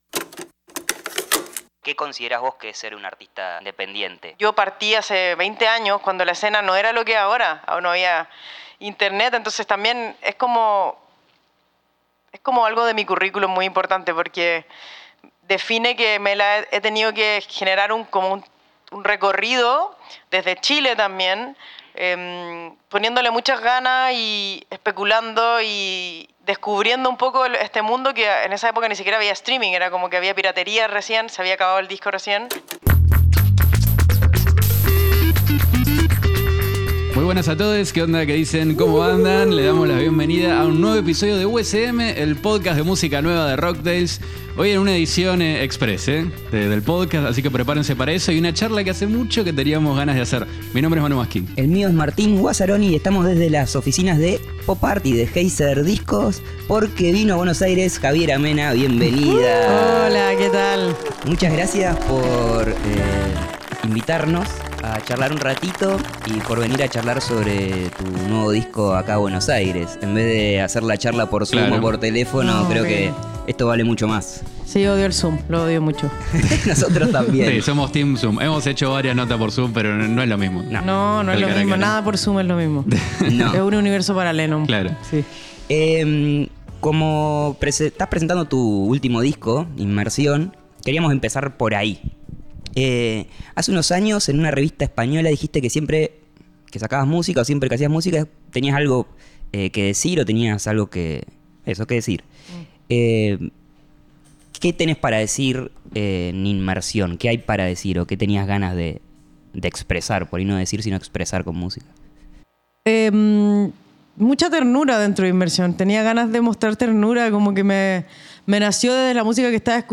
entrevista-javiera-mena-inmersion-full-album.mp3